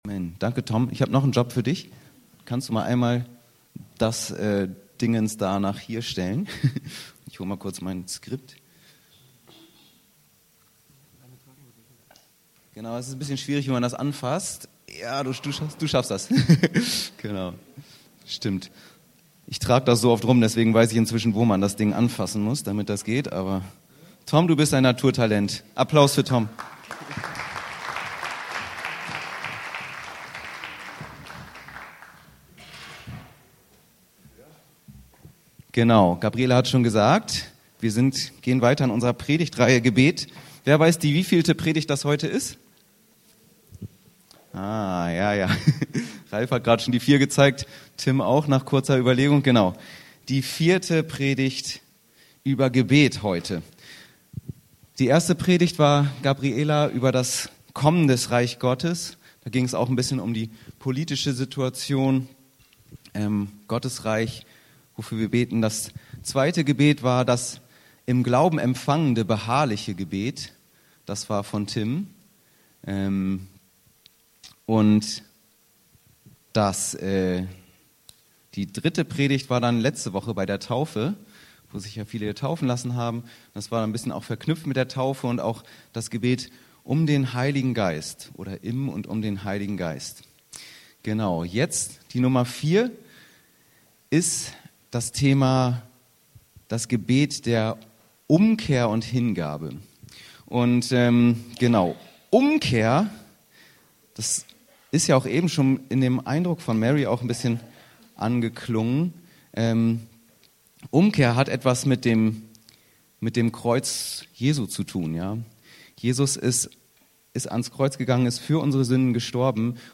Predigtreihe: Gebet, Teil 4: Das Gebet der Umkehr und Hingabe - Matthäus 6, 12 & Lukas 7, 36ff